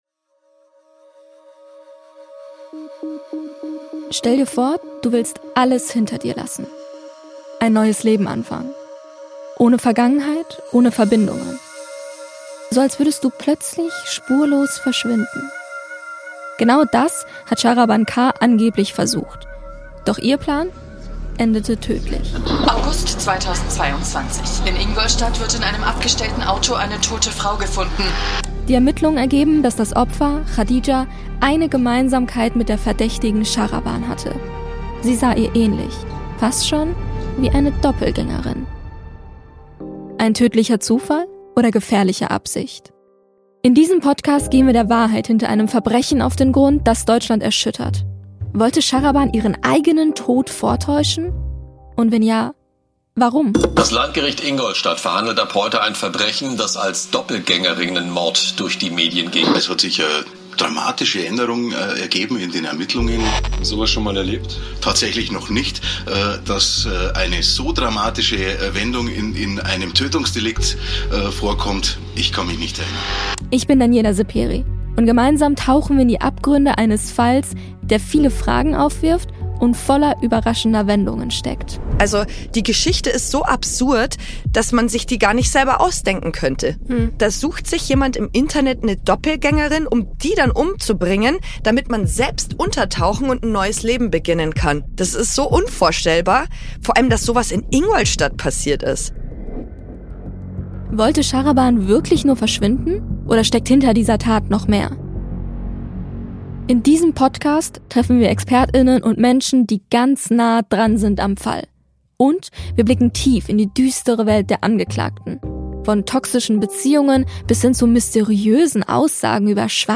Trailer